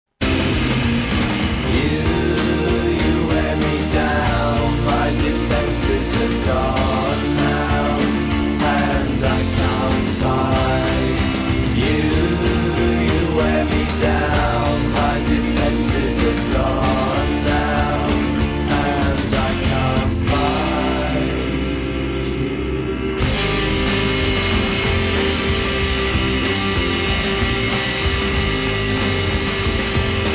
Indie Rock / Baggie / Brit Pop Classic Lp Reissue